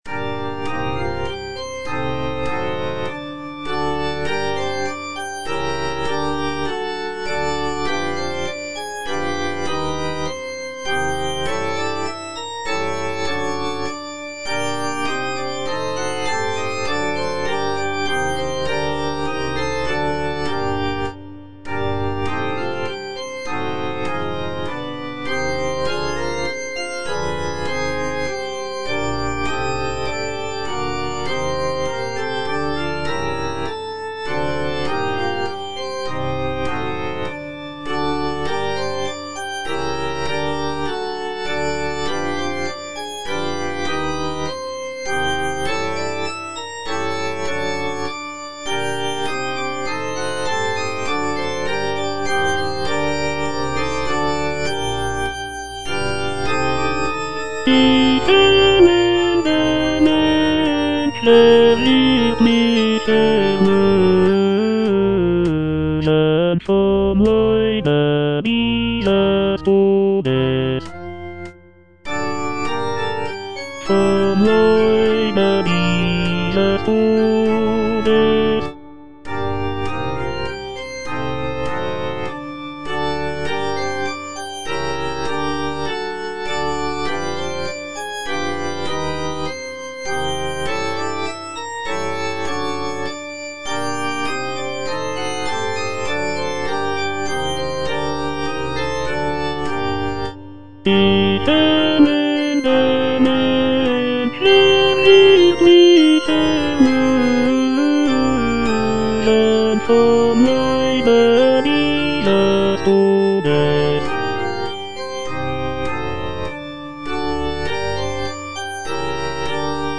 Cantata
Tenor (Voice with metronome) Ads stop